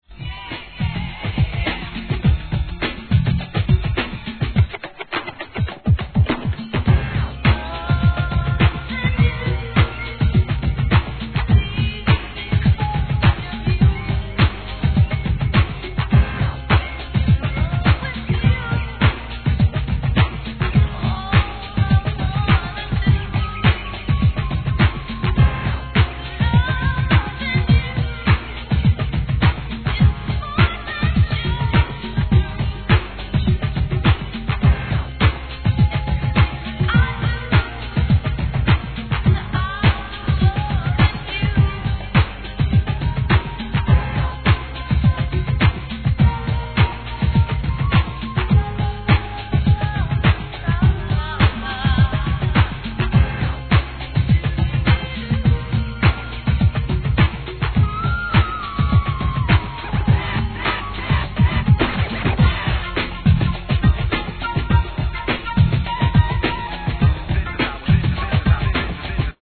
HIP HOP/R&B
が飛び出す人気曲REMIX!!